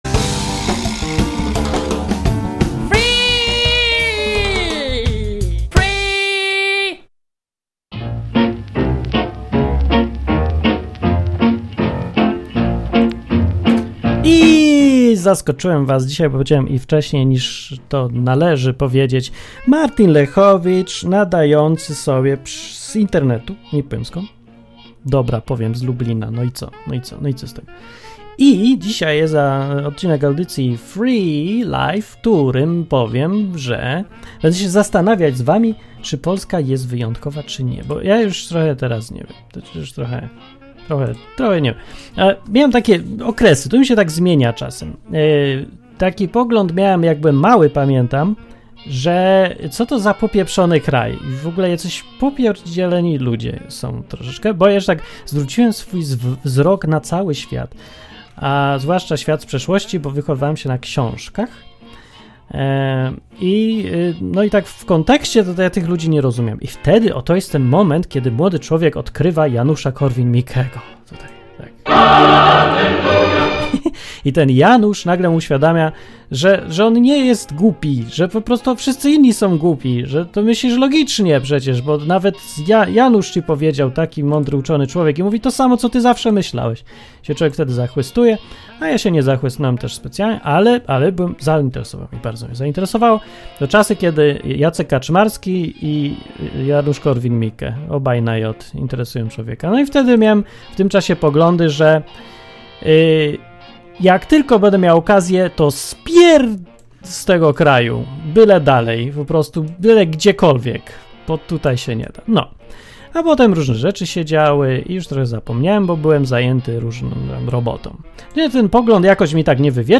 Program dla wszystkich, którzy lubią luźne, dzikie, improwizowane audycje na żywo.